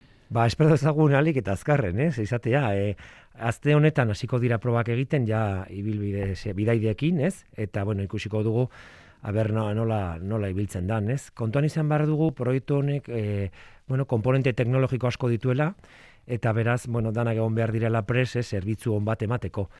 Entrevistado en Euskadi Irratia, Gorka Urtaran, alcalde de Vitoria-Gasteiz, ha asegurado que esta semana comienzan las pruebas definitivas del Bus Eléctrico Inteligente, ya con viajeros abordo.